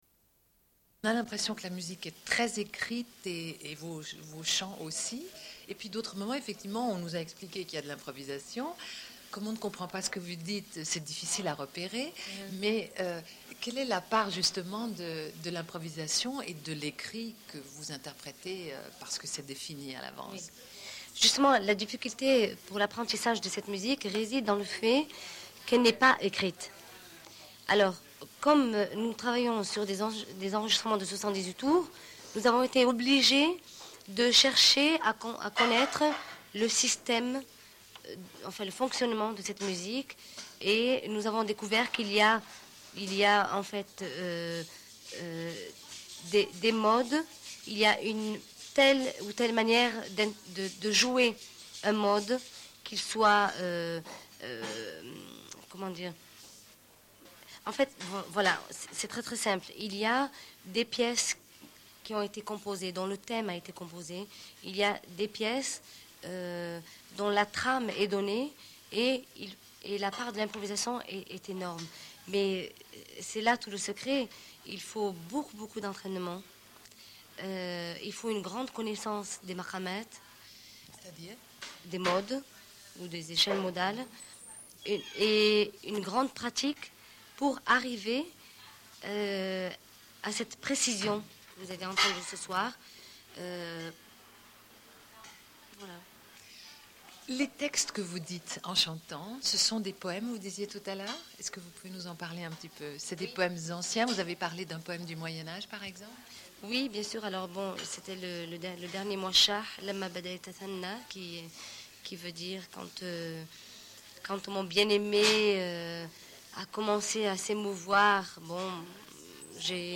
Appel pour les droits des femmes afghanes et lecture de poèmes pachtounes. Témoignage d'une enseignante burkinabée.